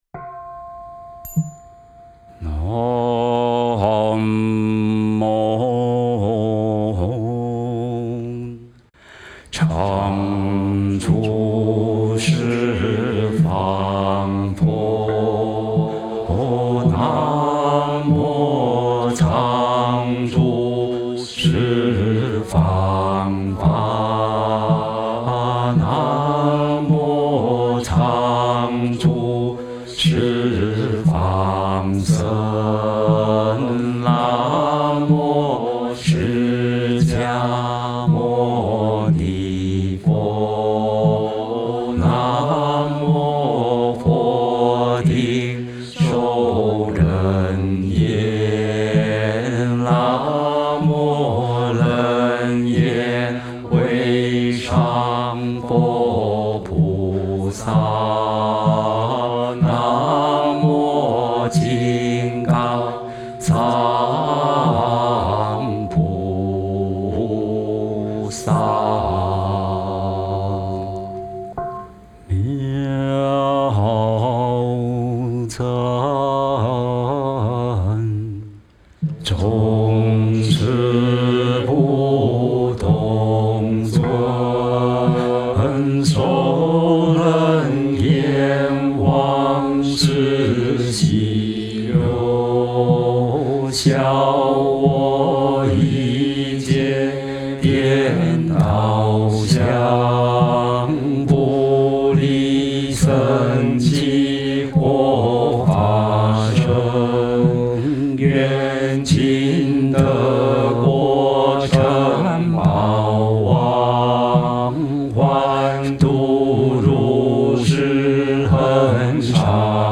梵呗教学音档